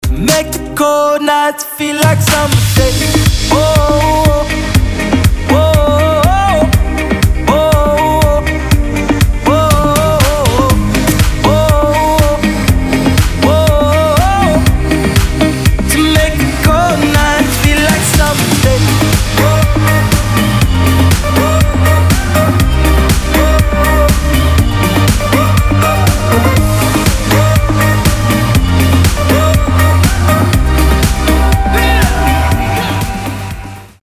позитивные
Electronic
alternative
Electronic Rock